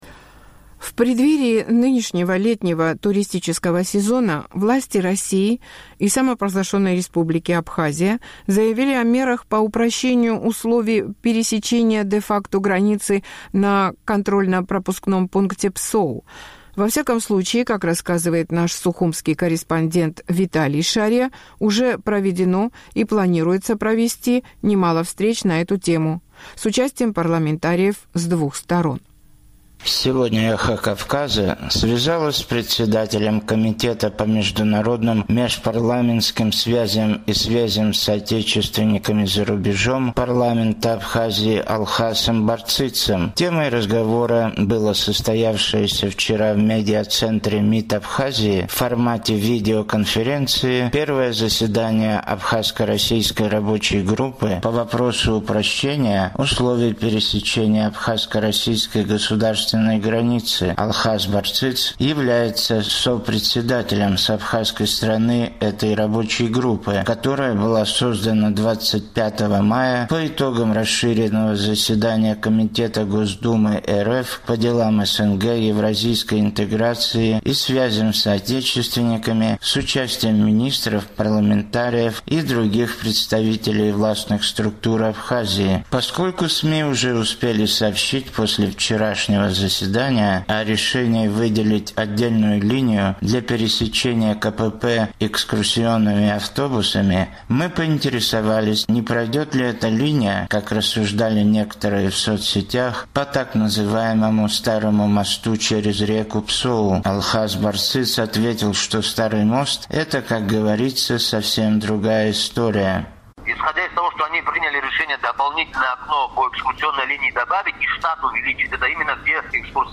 Сегодня «Эхо Кавказа» связалось с председателем комитета по международным, межпарламентским связям и связям с соотечественниками за рубежом парламента Абхазии Алхасом Барцицем. Темой разговора было состоявшееся вчера в медиацентре МИД Абхазии в формате видеоконференции первое заседание абхазско-российской рабочей группы по вопросу упрощения условий пересечения абхазско-российской государственной границы.